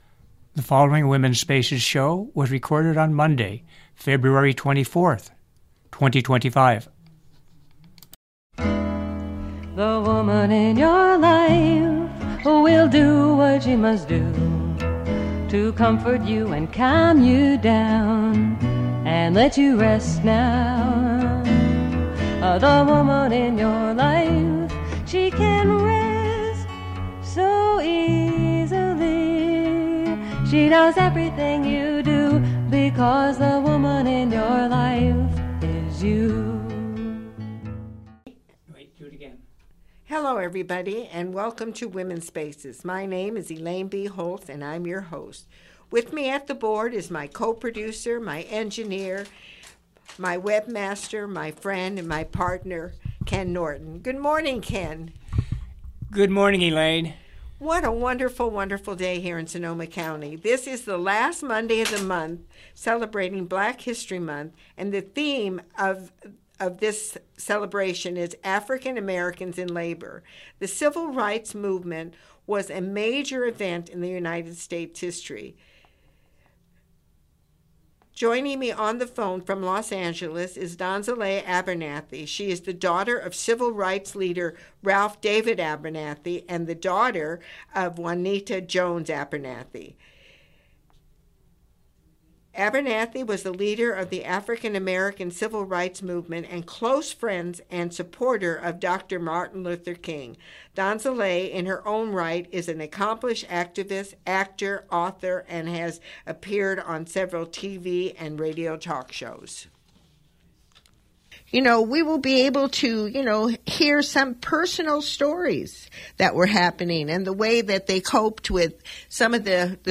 Joining me on the phone from Los Angeles is Donzaleigh Abernathy.